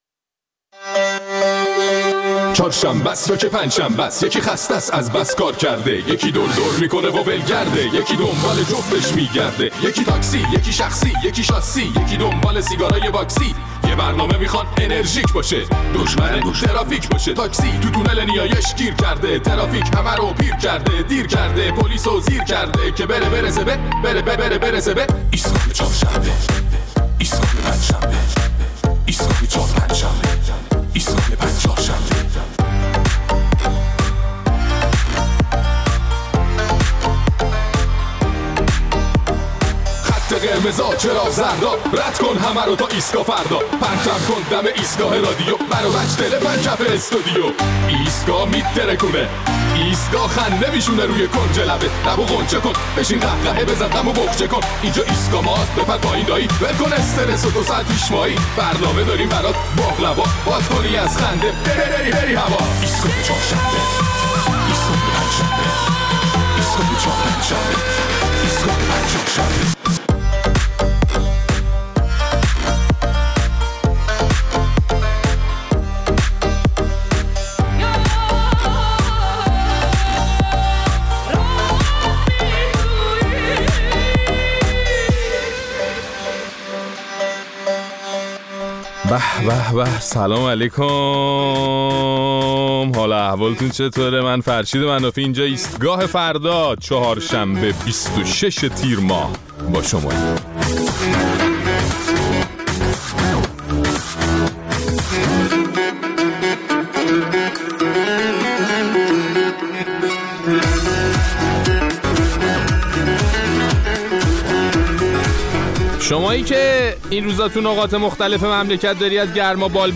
در این برنامه نظرات مخاطبین ایستگاه فردا را در مورد اظهارات اخیر حسن روحانی پرسیده‌ایم که از مردم خواسته بود مثل ‌ژاپنی‌ها روزی پنج ساعت رایگان کار کنند.